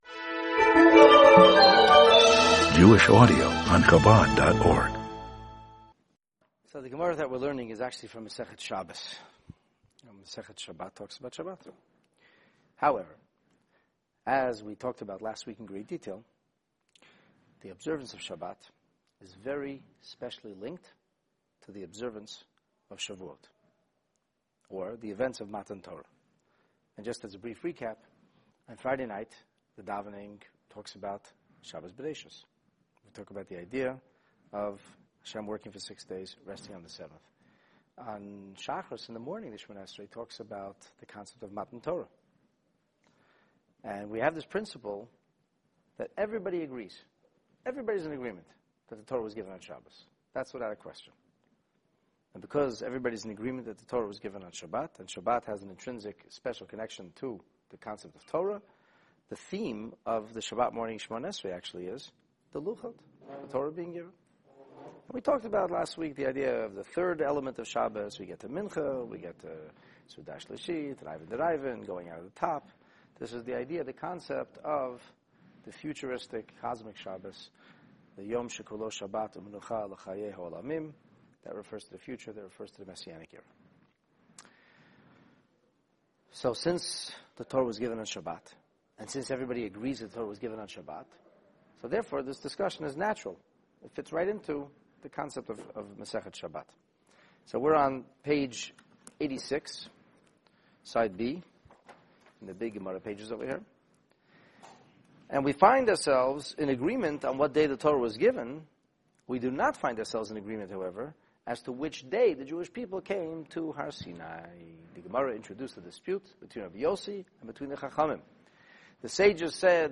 Exceptionally Moses!: The Talmud on the giving of the Torah (Part 1) In this Talmud class we’re onto a Shavuot track with teachings from Tractate Shabbat (87a) that narrate the events preceding the revelation at Sinai, and records a dispute over the exact date of this historic day. The cause for this difference of opinion was the divergent opinions regarding the lunar cycle, but as a result, Moses emerges as a unique prophet who was able to figure out the will of G-d on his own.